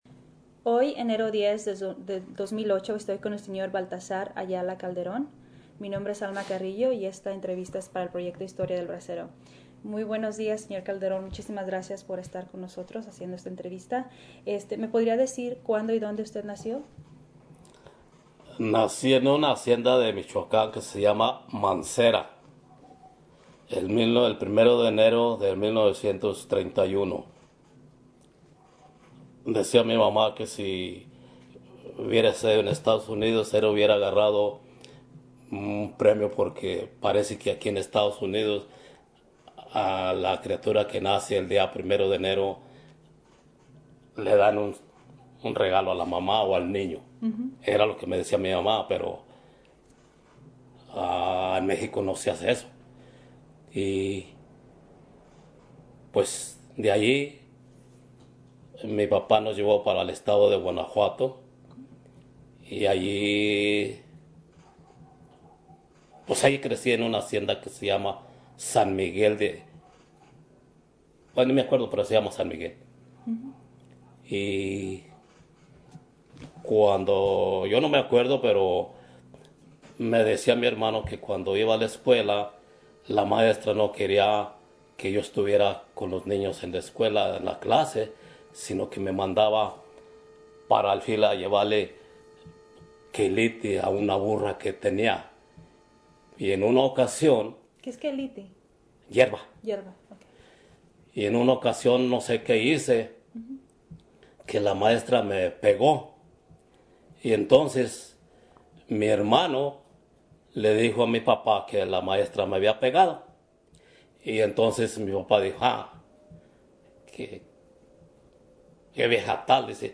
Location Tolleson, Arizona